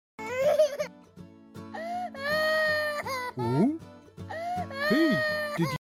WTF sound effects free download